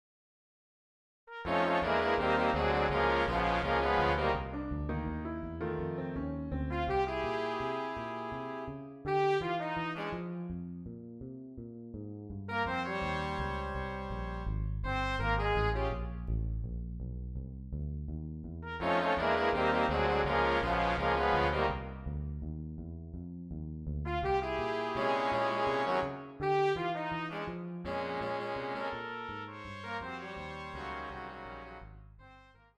An "in your face disgrace" for full big band!
Genre: Jazz - Swing Novelty Number
Eight and Five Big Band Chart with special effects.